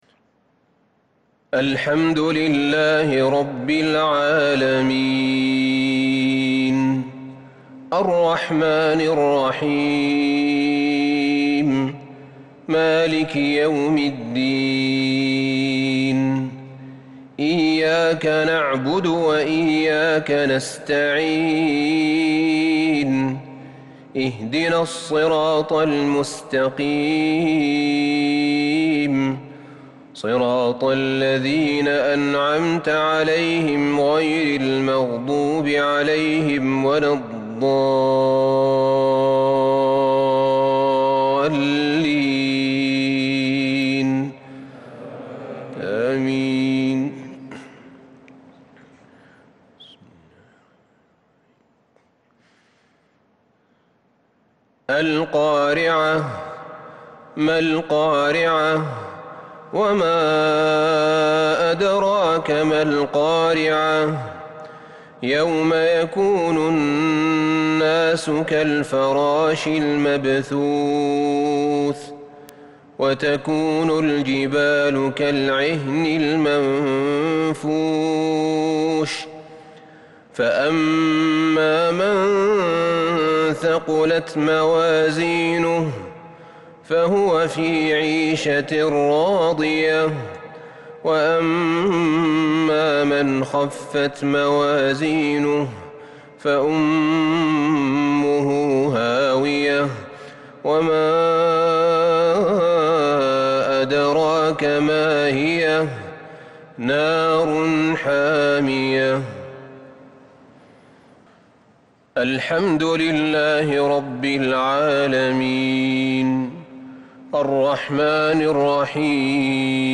مغرب الاربعاء 28 شوال 1442هـ سورتي القارعة والتكاثر | Maghrib prayer from Surat al-Qara'a and al-Takathur 9-6-2021 > 1442 🕌 > الفروض - تلاوات الحرمين